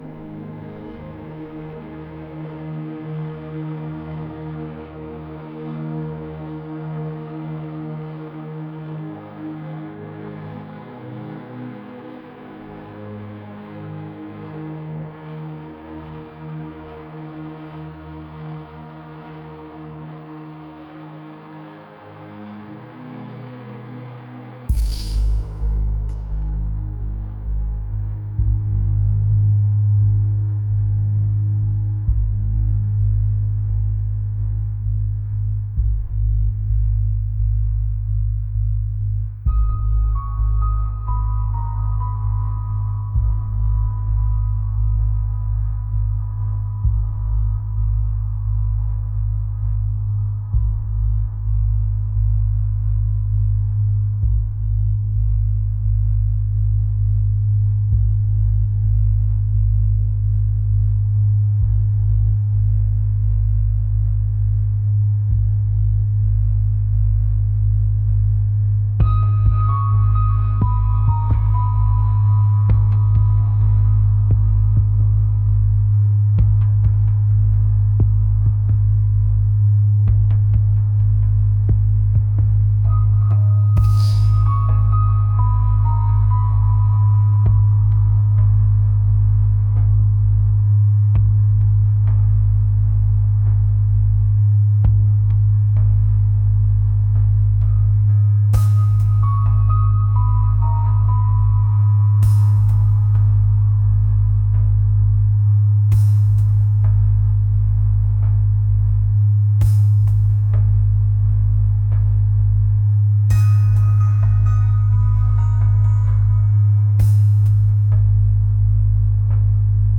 ethereal | atmospheric